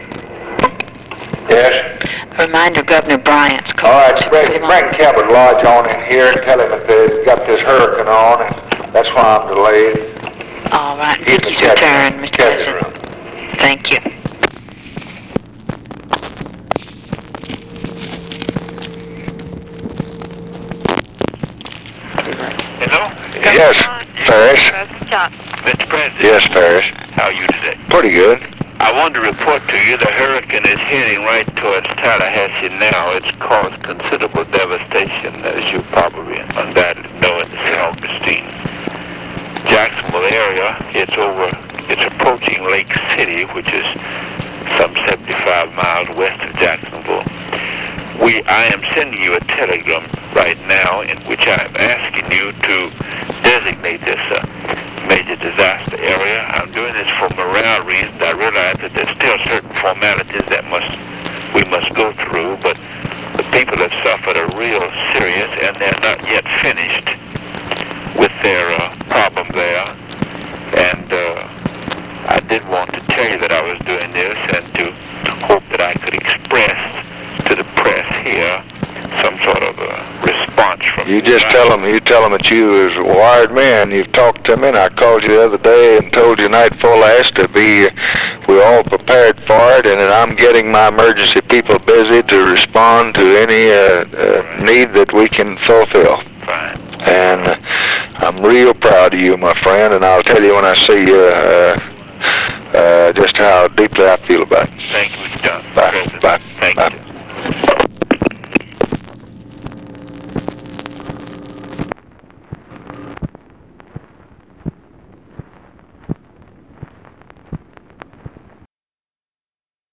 Conversation with FARRIS BRYANT and OFFICE SECRETARY, September 10, 1964
Secret White House Tapes